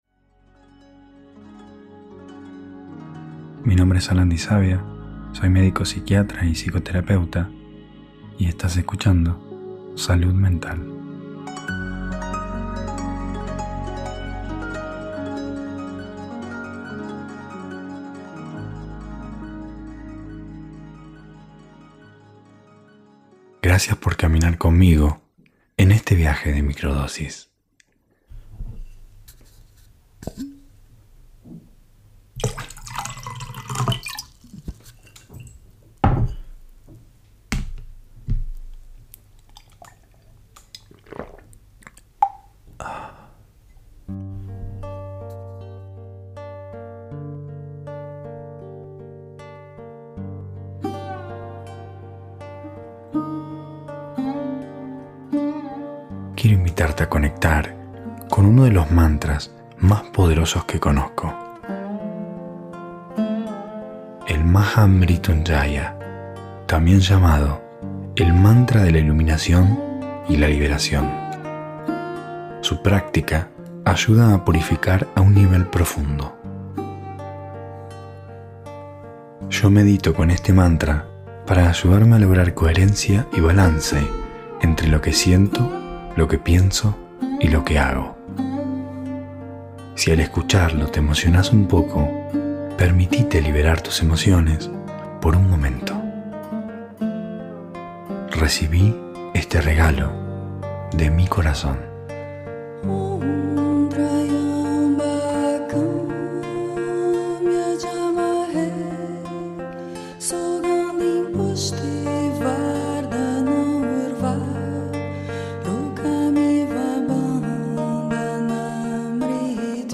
Ritual de Microdosis guiado - Emociones